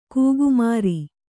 ♪ kūgu māri